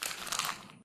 paper_scrunch_1_quieter.ogg